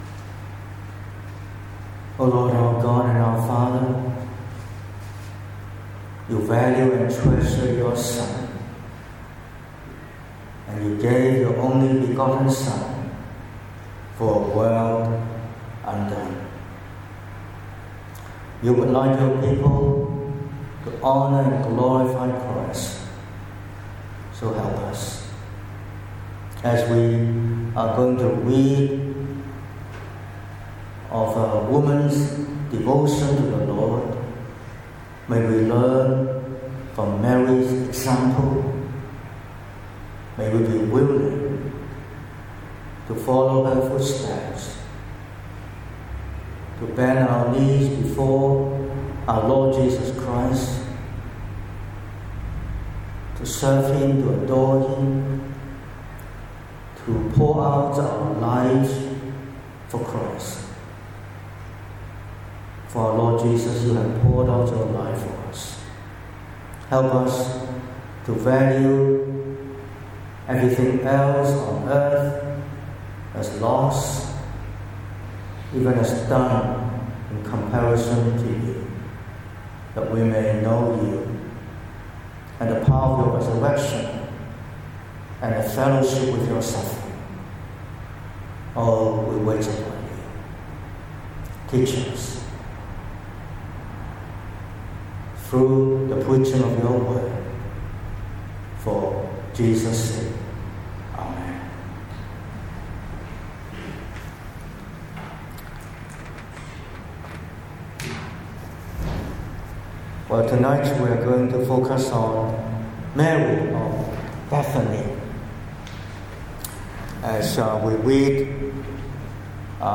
07/12/2025 – Evening Service: Mary of Bethany